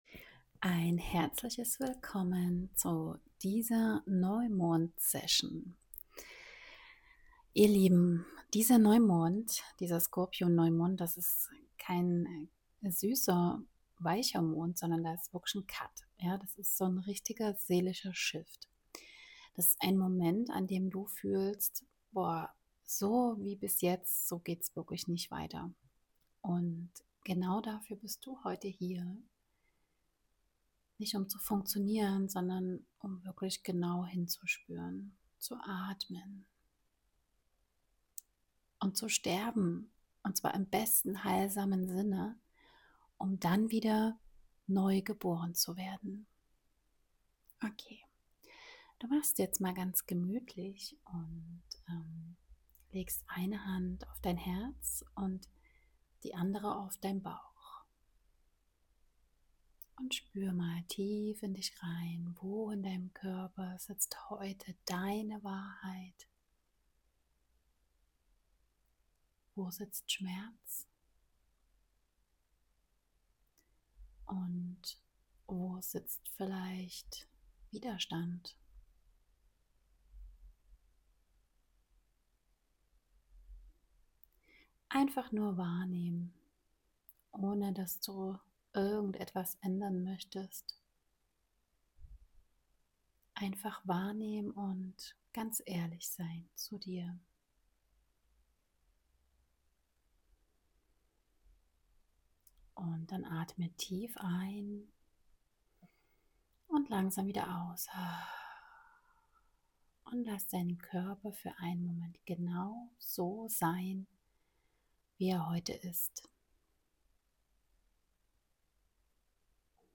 🜁 ein sanftes Somatic Drop-In
🜁 eine regulierende Breathworkphase